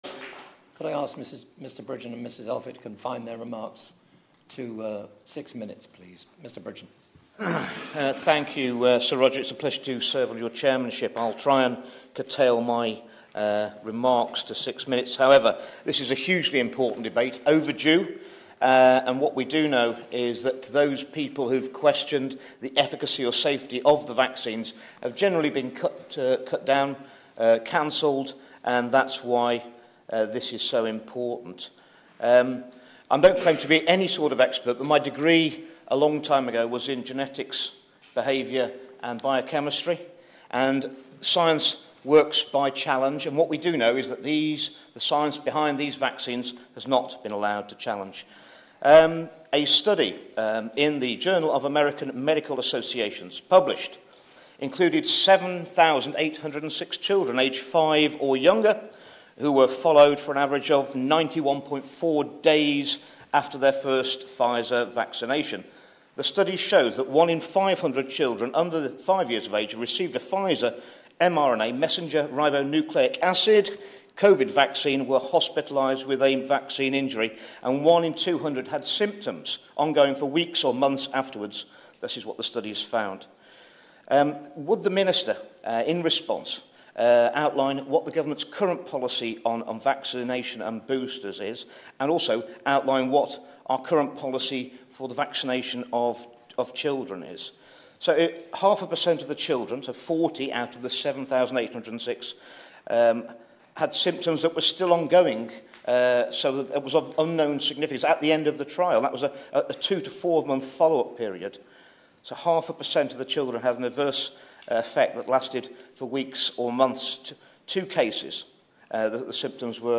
Andrew Bridgen MP speaks in an e-petition debate relating to the safety of covid-19 vaccines and raises concerns that the science has not been permitted to be sufficiently challenged and notes that studies in Florida have resulted in the state recommending that they do not vaccinate males under 40.